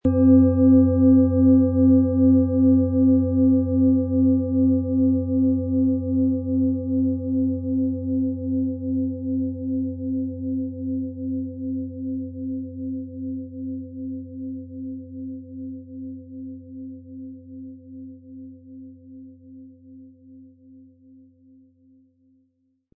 • Tiefster Ton: Jupiter
• Höchster Ton: Sonne
PlanetentöneDNA & Jupiter & Sonne (Höchster Ton)
MaterialBronze